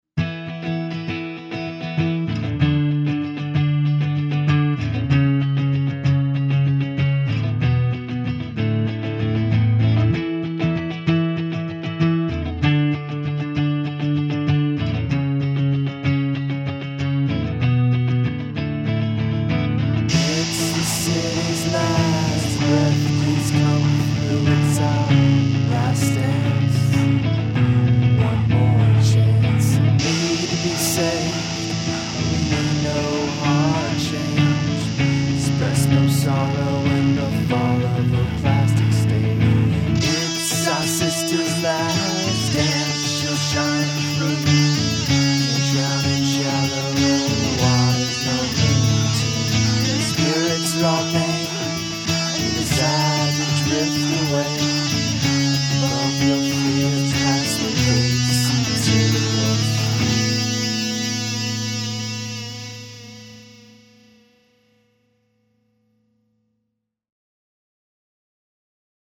Vocals, Guitar
Bass, Drums
Recorded and mixed in our basement